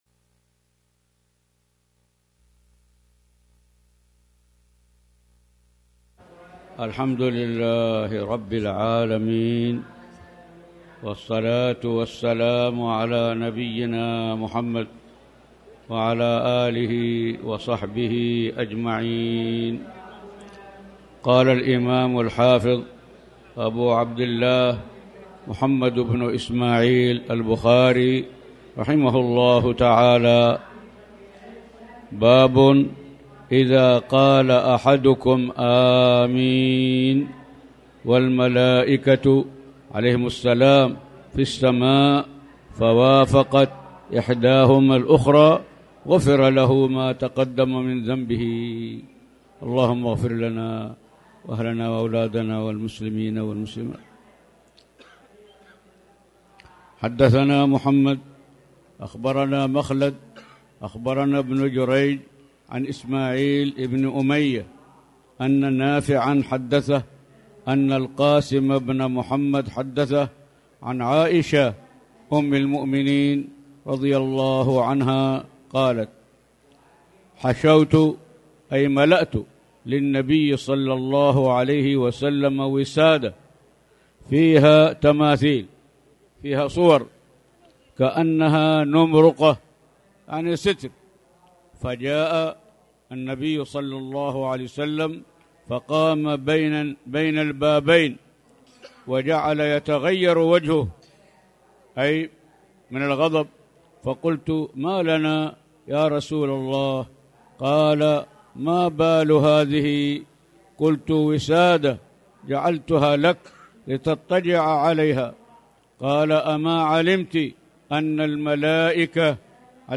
تاريخ النشر ١٢ صفر ١٤٣٩ هـ المكان: المسجد الحرام الشيخ